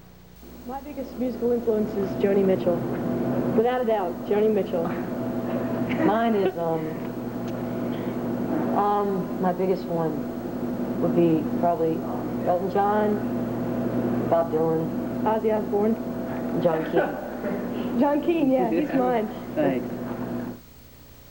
02. interview (0:21)